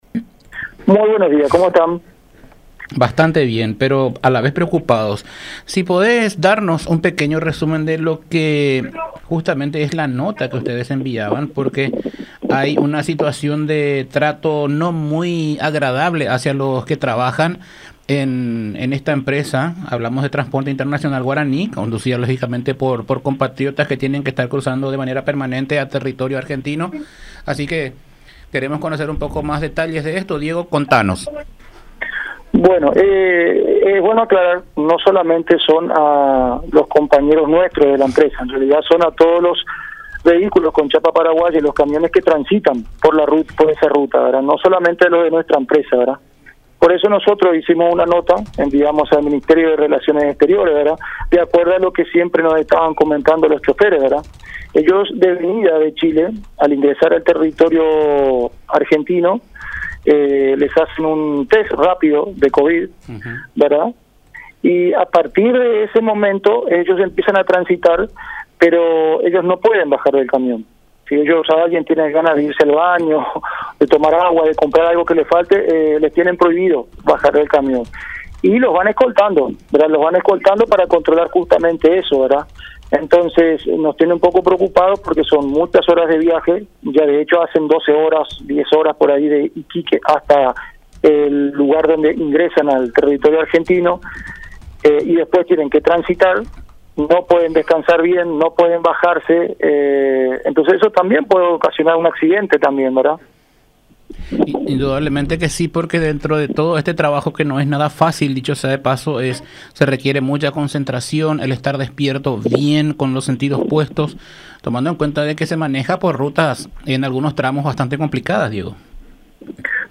en comunicación con La Unión R800 AM.